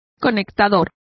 Complete with pronunciation of the translation of connector.